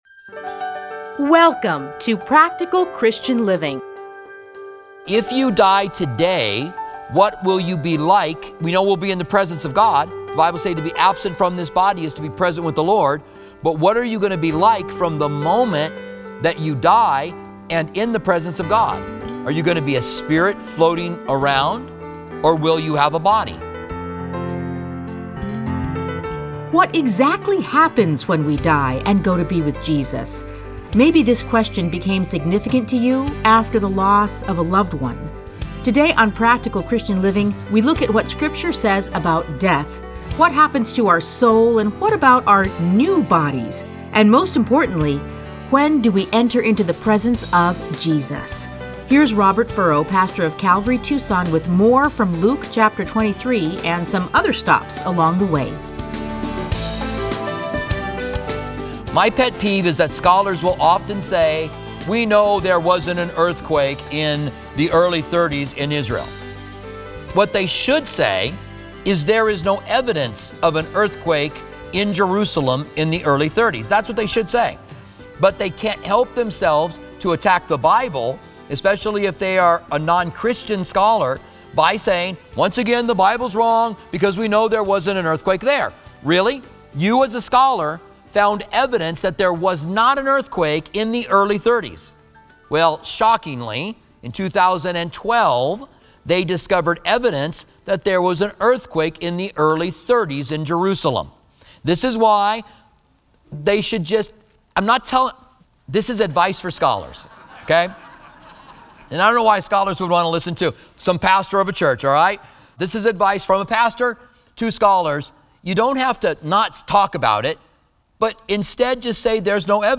Listen to a teaching from Luke 23:44-49.